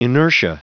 Prononciation du mot inertia en anglais (fichier audio)
Prononciation du mot : inertia